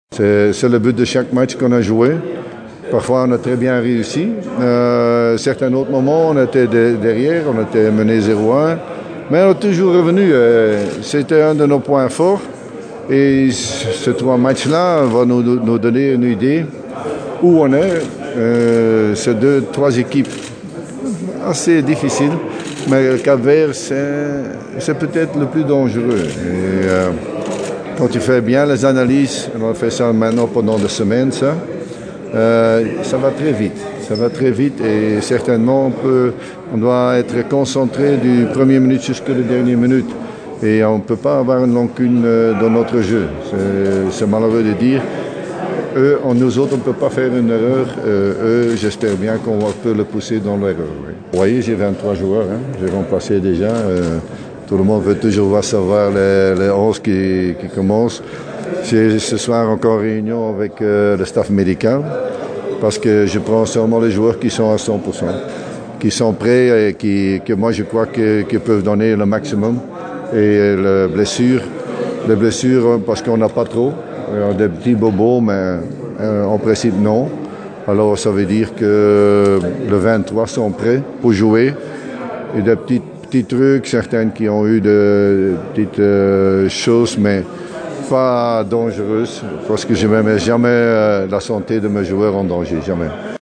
إعتبر مدرب المنتخب الوطني لكرة القدم جورج ليكنز خلال الندوة الصحفية التي عقدها البارحة في إيبيبيين أن منتخب الرأس الأخضر هو أخطر منتخب في مجموعتنا في كأس إفريقيا للأمم التي تدور حاليا في غينيا الإستوائية .